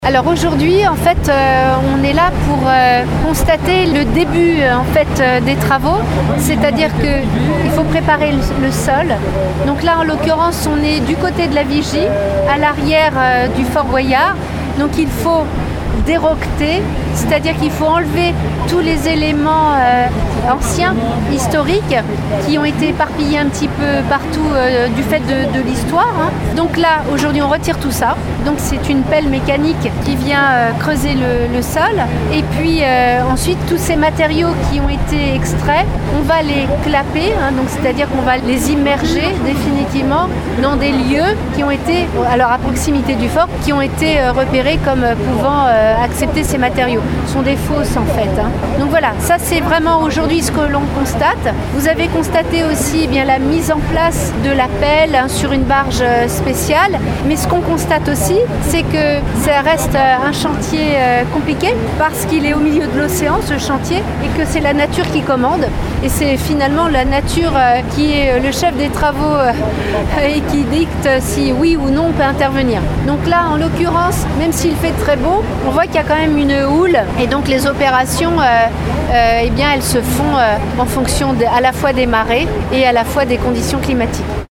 Sylvie Marcilly nous en dit plus :